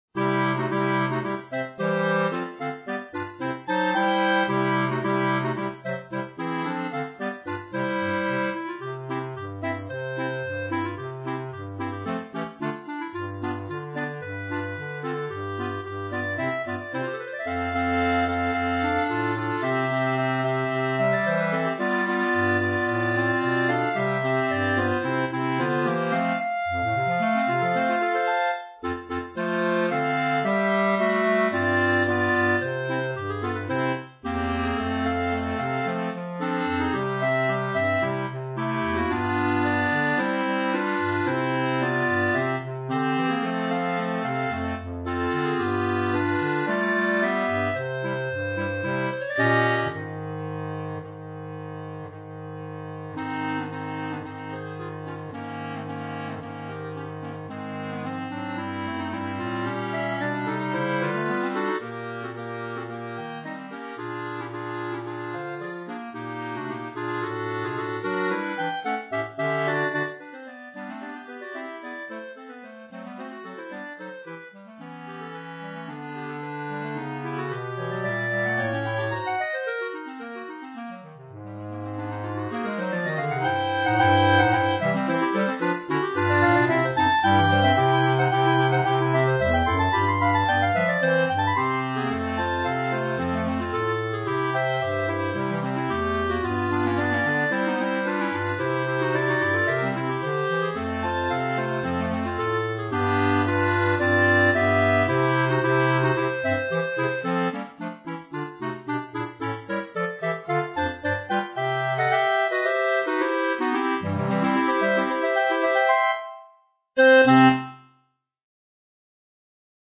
B♭ Clarinet 1 B♭ Clarinet 2 B♭ Clarinet 3 Bass Clarinet
单簧管四重奏
它有着约德尔（Yodel）般的音型，听起来欢快有趣，让人忍不住想跟着哼唱。